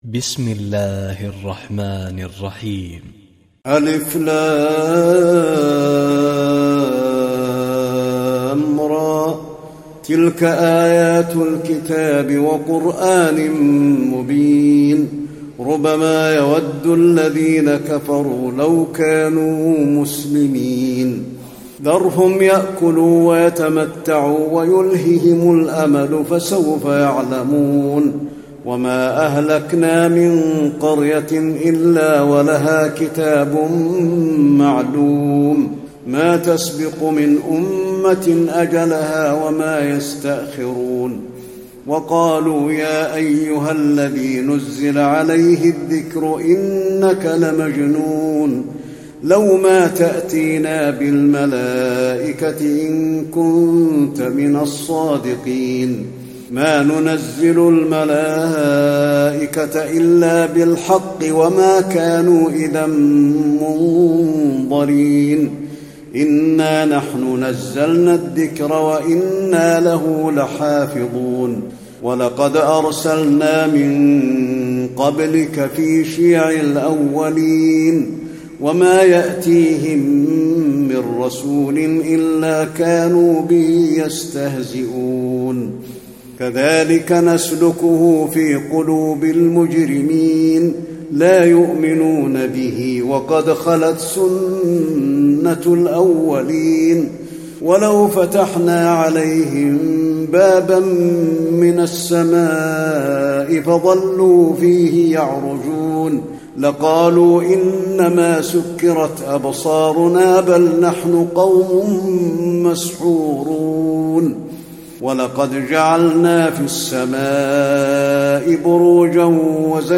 تراويح الليلة الثالثة عشر رمضان 1435هـ من سورتي الحجر كاملة و النحل (1-55) Taraweeh 13 st night Ramadan 1435H from Surah Al-Hijr and An-Nahl > تراويح الحرم النبوي عام 1435 🕌 > التراويح - تلاوات الحرمين